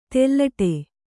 ♪ tellaṭe